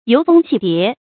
游蜂戲蝶 注音： ㄧㄡˊ ㄈㄥ ㄒㄧˋ ㄉㄧㄝ ˊ 讀音讀法： 意思解釋： ①指圍著花叢飛舞游動的蜜蜂和蝴蝶。